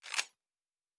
Weapon 10 Foley 2.wav